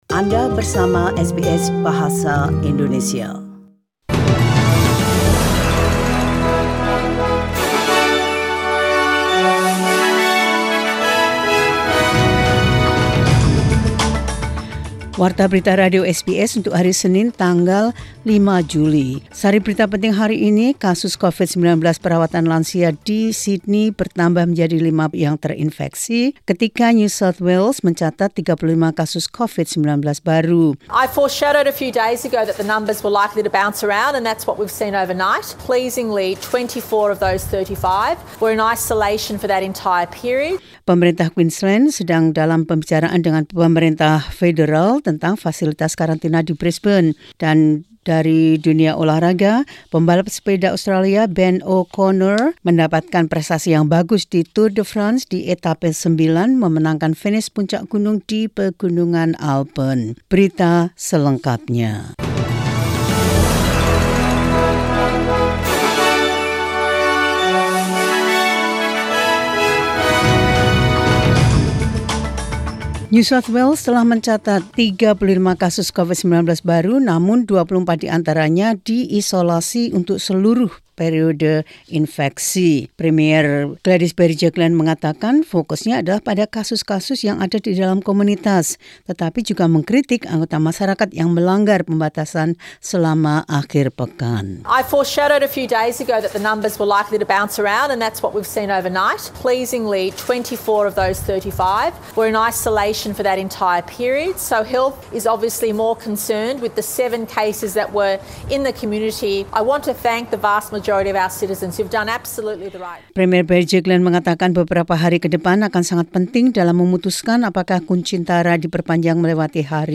Warta Berita Radio SBS Program Bahasa Indonesia –05 Jul 2021